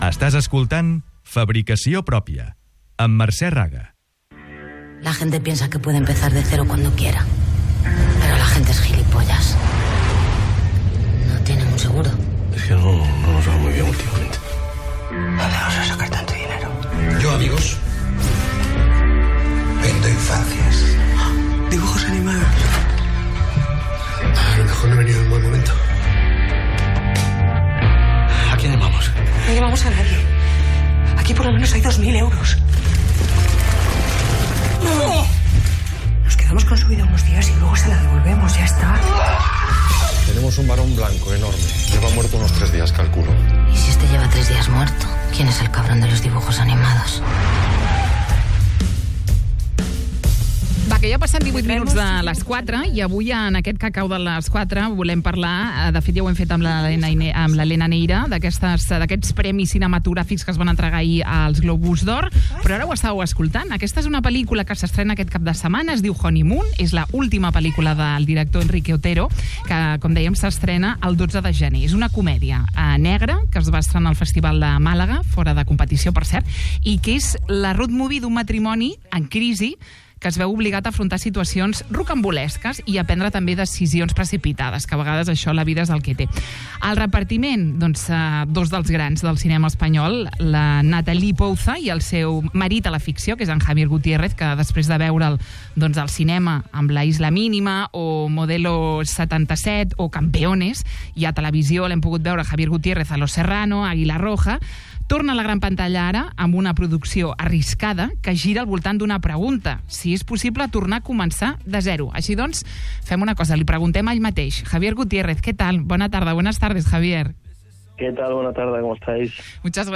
En una entrevista al 'Fabricació Pròpia' de Ràdio Estel, l'actor aplaudeix la feina de la seva companya.